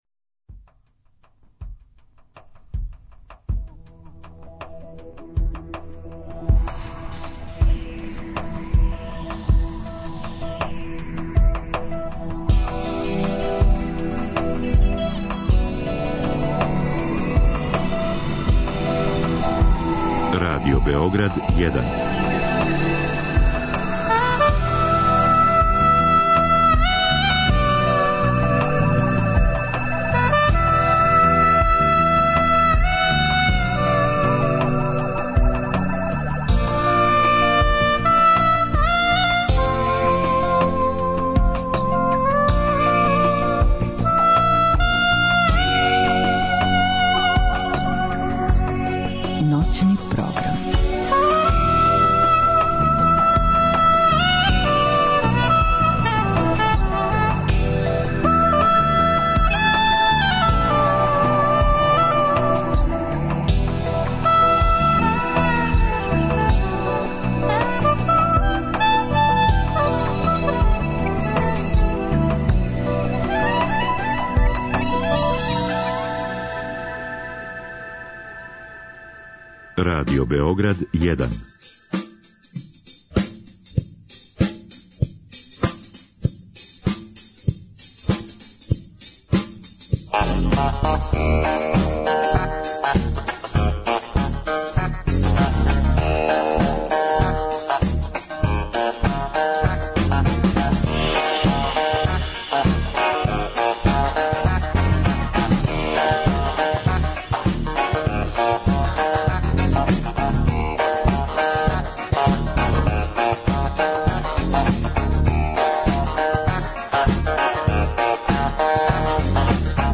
са слушаоцима ће бити водитељи и гости у студију